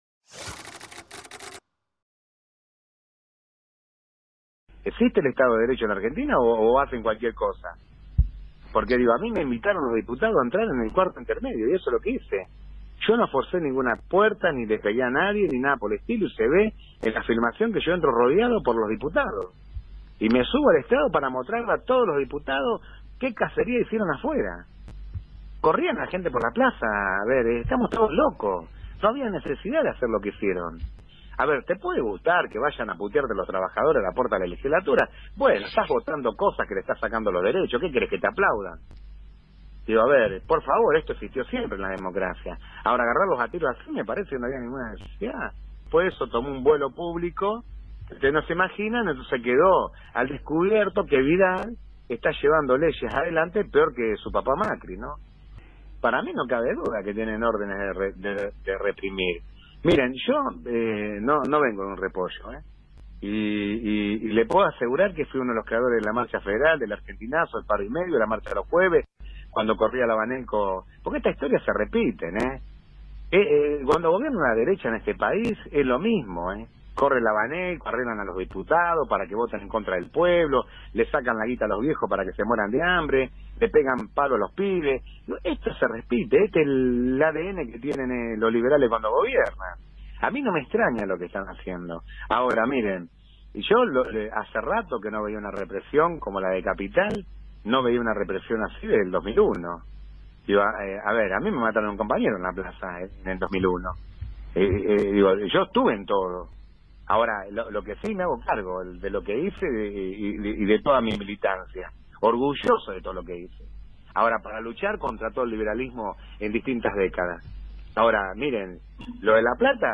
(Audio Radial del intendente de Ensenada, hablando de la Persecución en su contra)